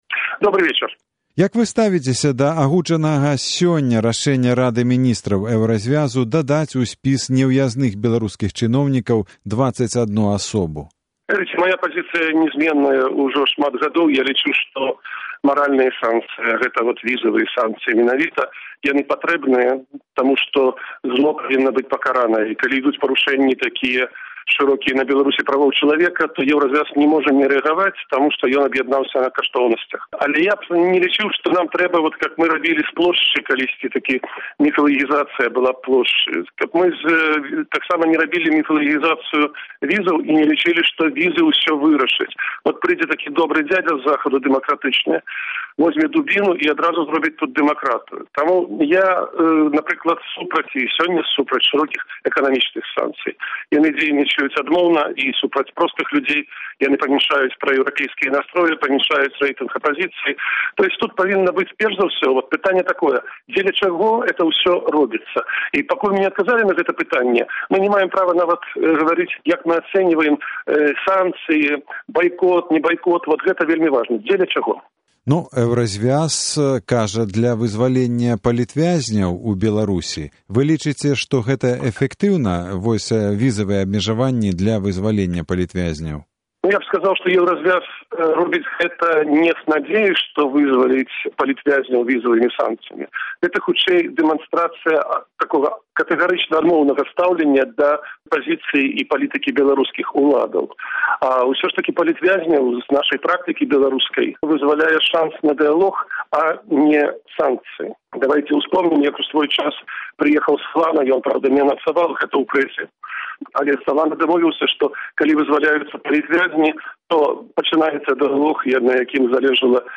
Інтэрвію з Аляксандрам Мілінкевічам